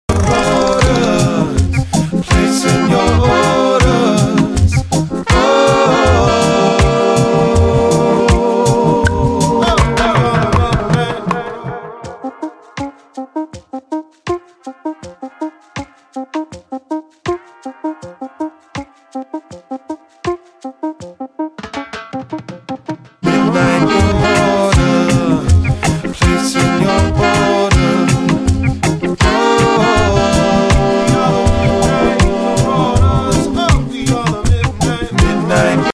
Synth Riff